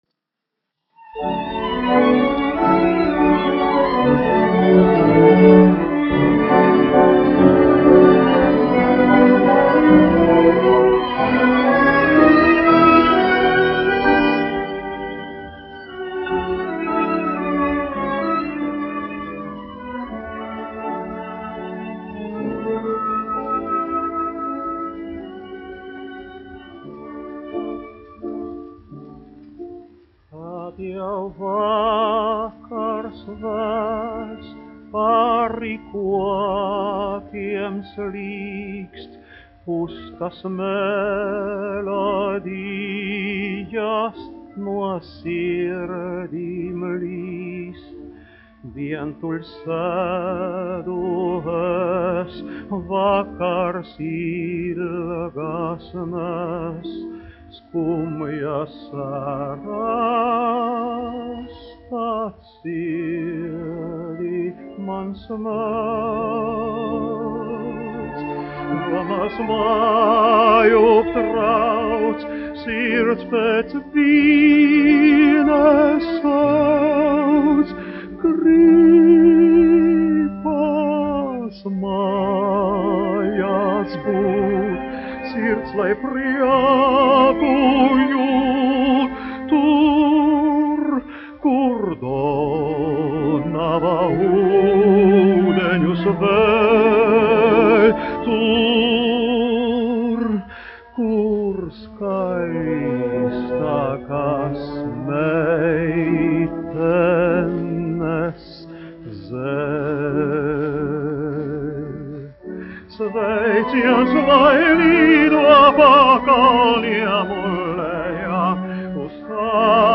1 skpl. : analogs, 78 apgr/min, mono ; 25 cm
Operetes--Fragmenti
Skaņuplate